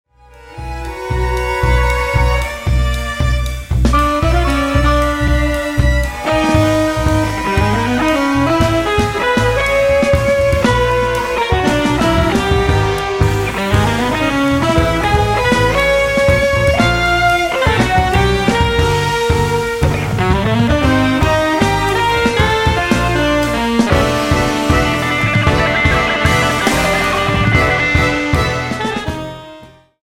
guitar/composition
piano
saxophone/clarinet
cello
drums
Sie ist sperrig, sie ist ambitioniert, sie ist großartig.